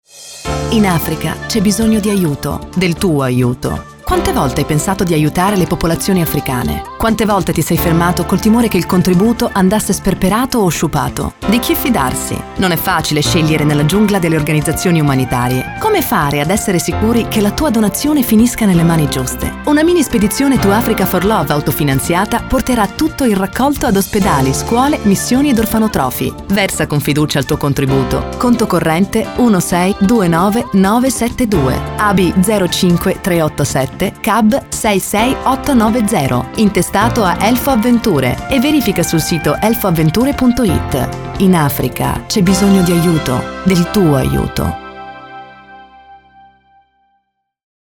In onda spot di sensibilizzazione a sostegno della campagna di raccolta fondi Elfoavventure.